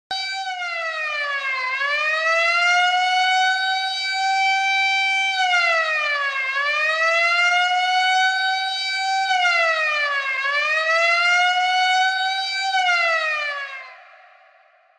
На этой странице собраны звуки тюрьмы — от хлопающих дверей камер до приглушенных разговоров в коридорах.
Звук сирены во время побега заключенного из тюрьмы